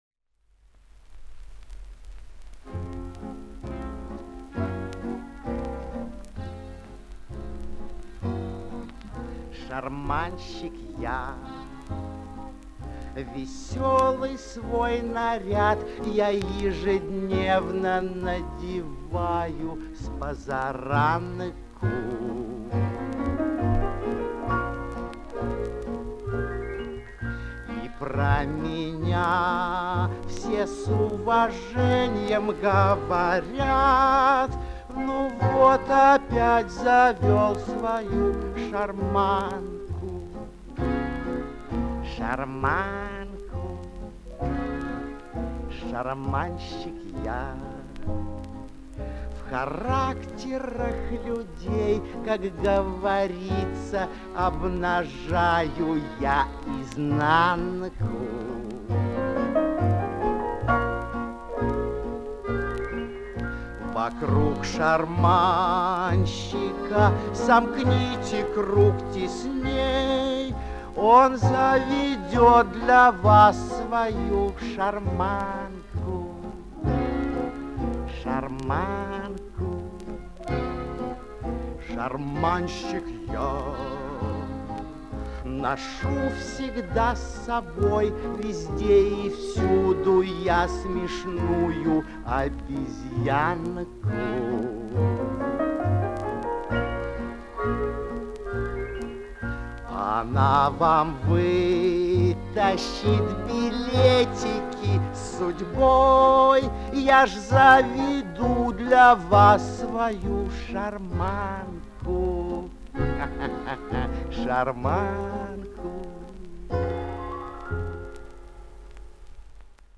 Запись 1950-х гг.